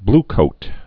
(blkōt)